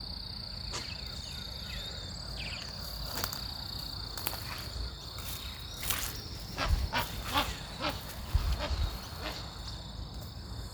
Rufescent Tiger Heron (Tigrisoma lineatum)
Detailed location: Villa Zorraquin
Condition: Wild
Certainty: Observed, Recorded vocal